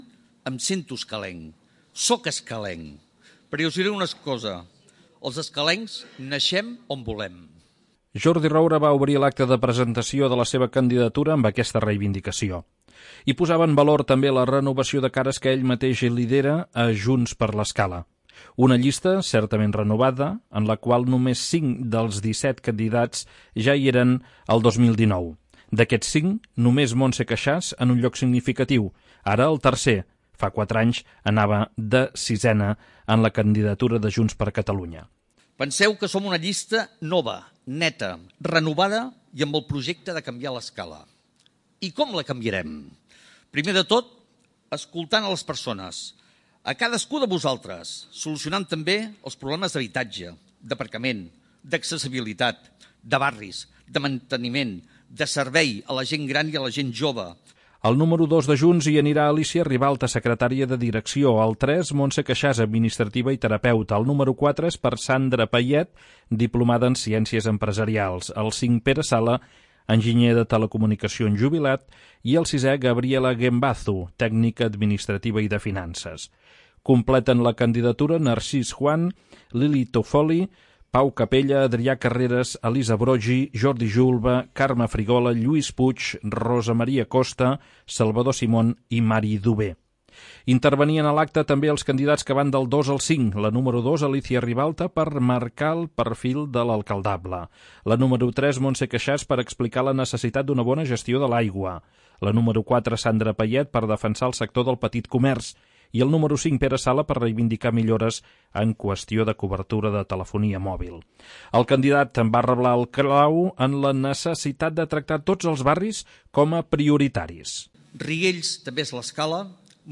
La presentació va tenir lloc a la sala d'actes de la Llar del Jubilat amb l'assistència de mig centenar de persones.